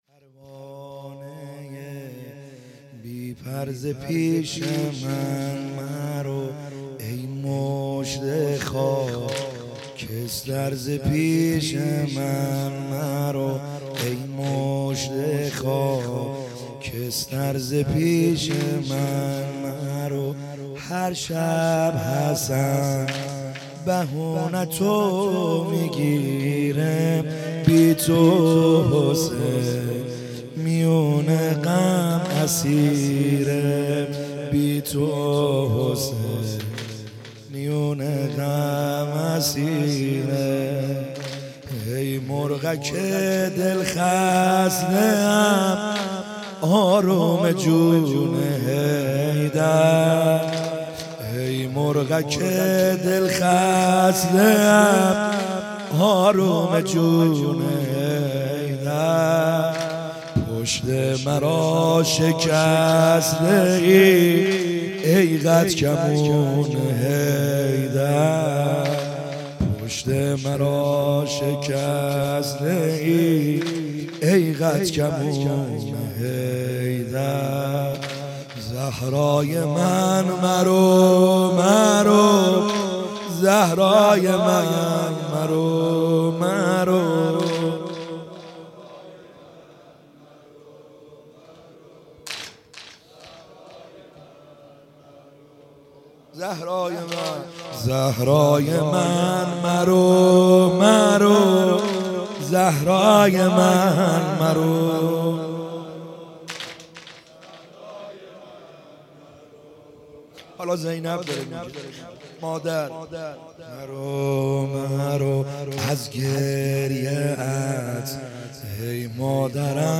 خیمه گاه - بیرق معظم محبین حضرت صاحب الزمان(عج) - سنگین | زپیشم مرو